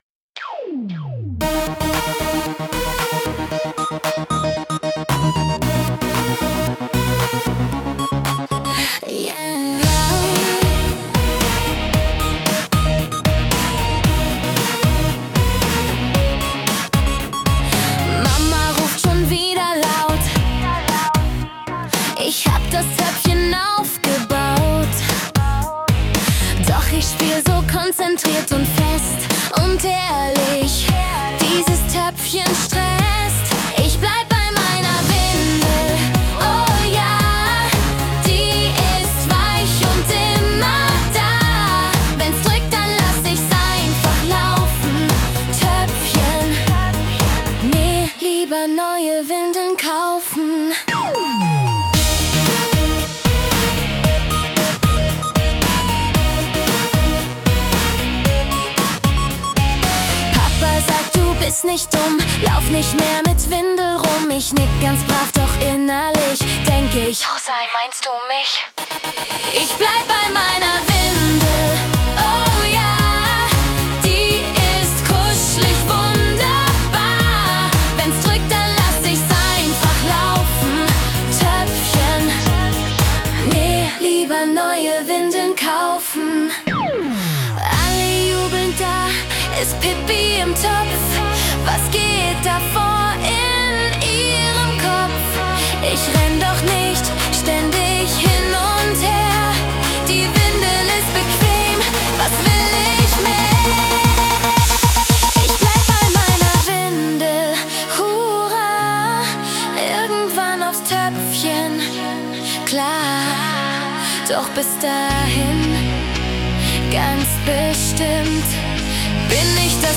witzig-verspielter Song